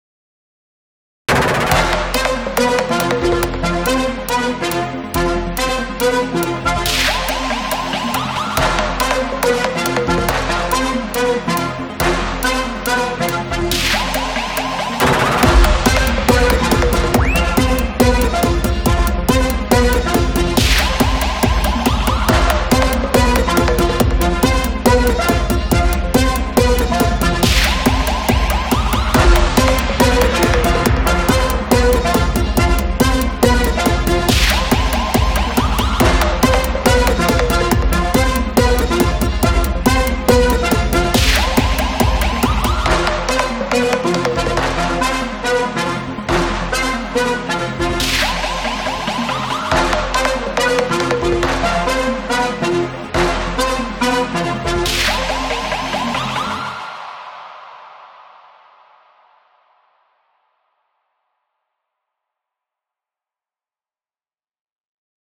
BGM
アップテンポインストゥルメンタルショート激しい